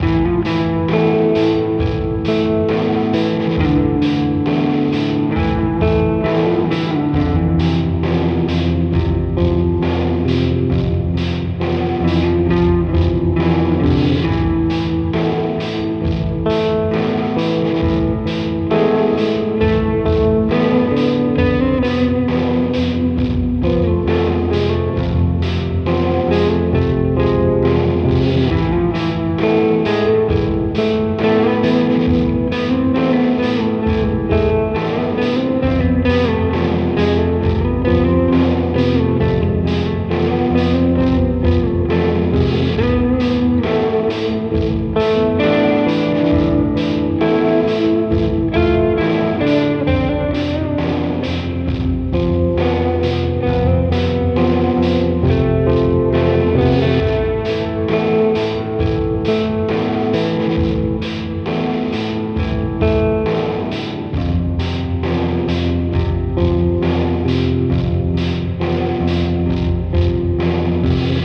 Chill thing on guitar while the sun was setting
Both guitars and drums going through separate Neural DSP Tone King instances